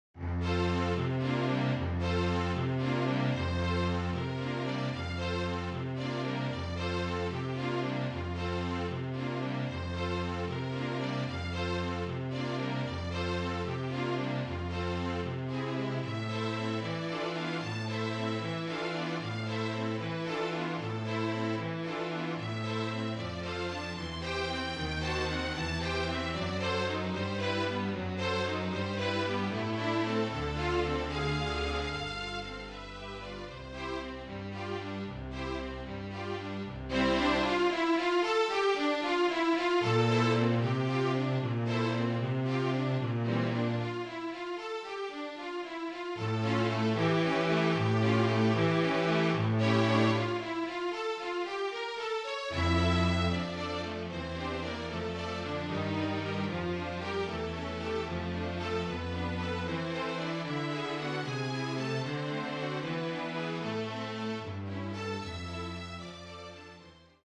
MIDI
for flute, violin, viola, cello and double bass